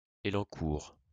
Élancourt (French pronunciation: [elɑ̃kuʁ]